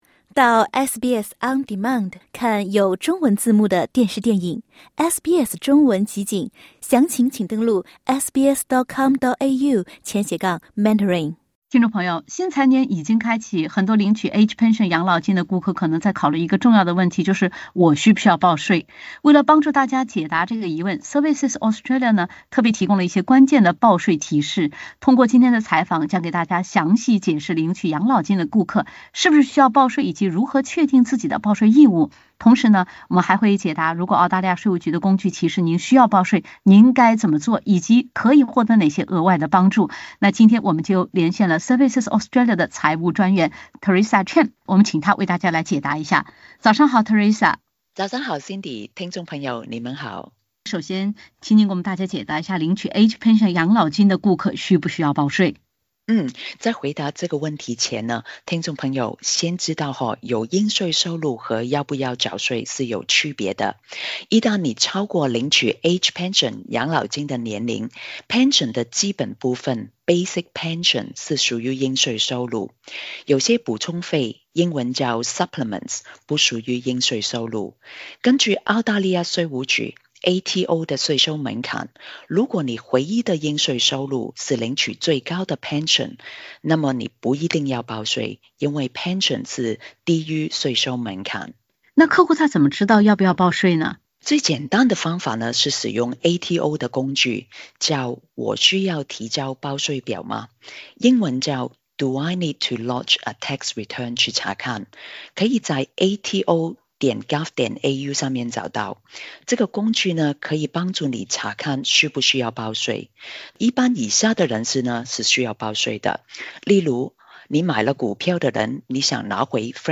Services Australia工作人员为大家详细解领取养老金的顾客是否需要报税，以及如何确定自己的报税义务。
通过采访，我们将为大家详细解释领取养老金的顾客是否需要报税，以及如何确定自己的报税义务。 同时，我们还将解答如果澳大利亚税务局的工具提示您需要报税，您该怎么办，以及可以获得哪些额外的帮助，确保您的报税过程顺利无误。